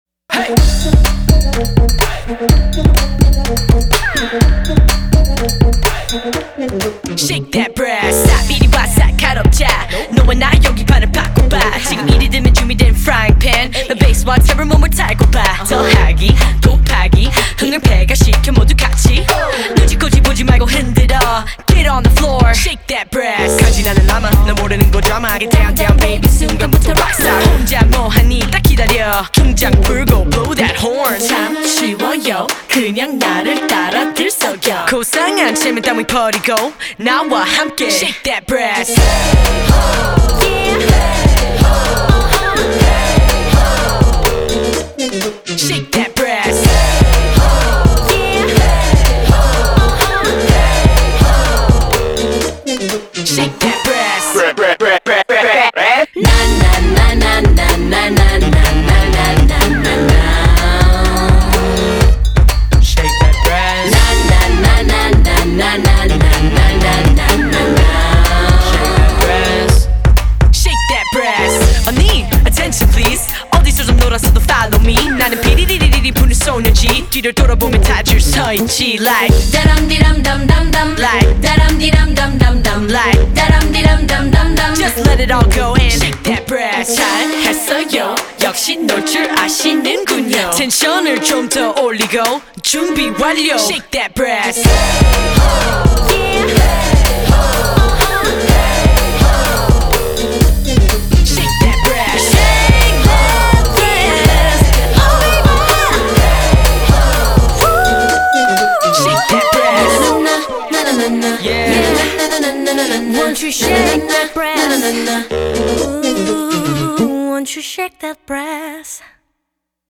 BPM125
Audio QualityPerfect (High Quality)